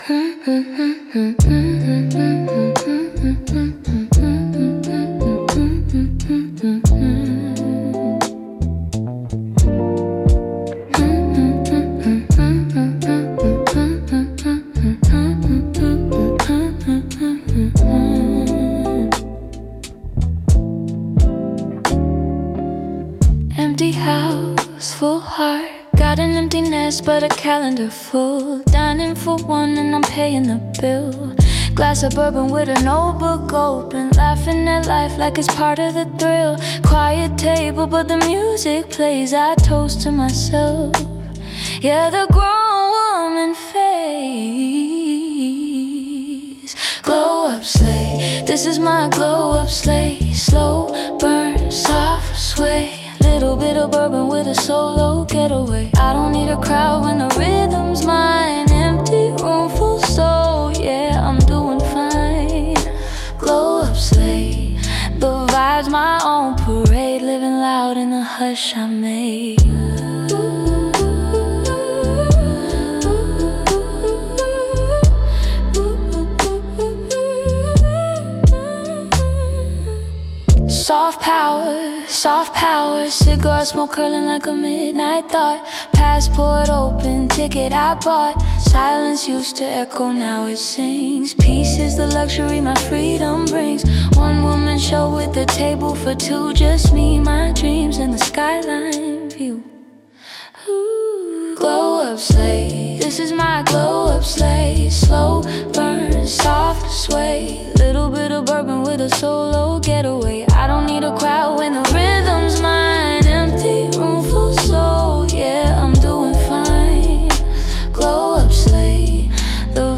Think R&B-inspired confidence beats, soft-jazz lofi loops, and the kind of audio that makes you feel like you’re stepping into your main-character moment.